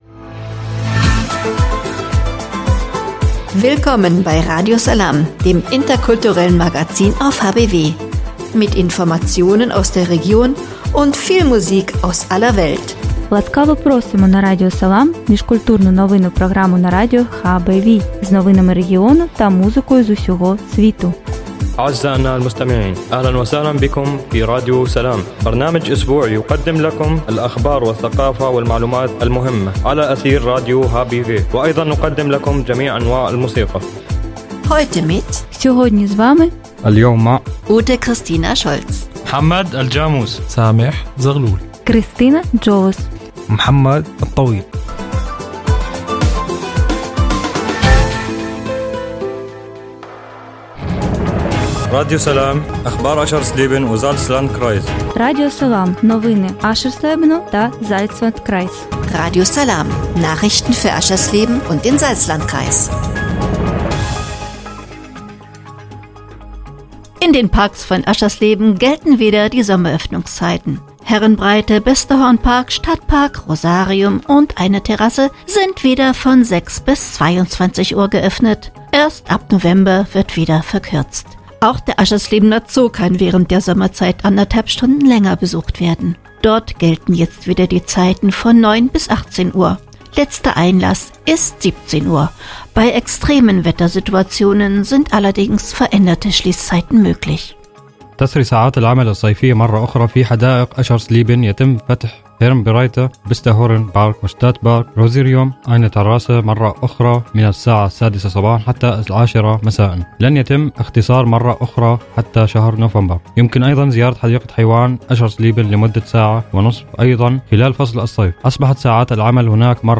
„Radio Salām“ heißt das interkulturelle Magazin auf radio hbw. Mit dem Wochenmagazin wollen die Macher alte und neue Nachbarn erreichen: diejenigen, die schon lange in Harz und Börde zu Hause sind, und ebenso Geflüchtete, beispielsweise aus Syrien.